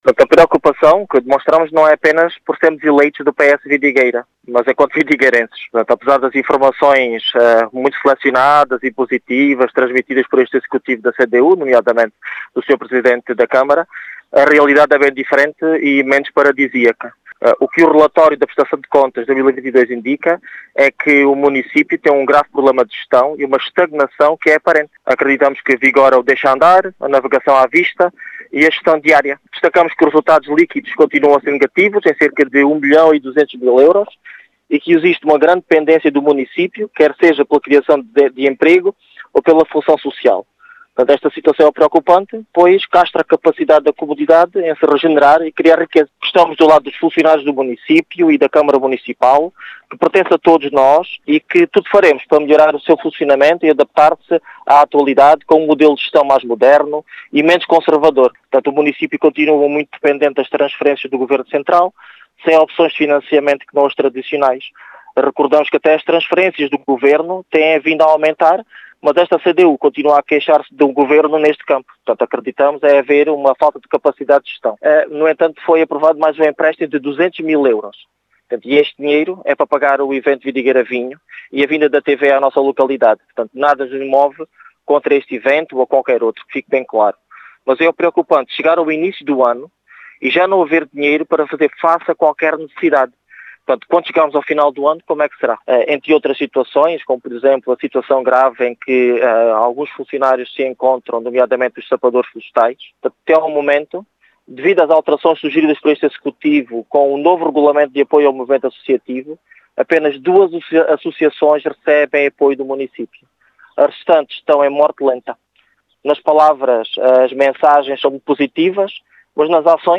As preocupações foram deixadas por Norberto Fialho, eleito do PS na Assembleia Municipal de Vidigueira fala num “grave problema de gestão” na Câmara Municipal de Vidigueira e numa “estagnação aparente”.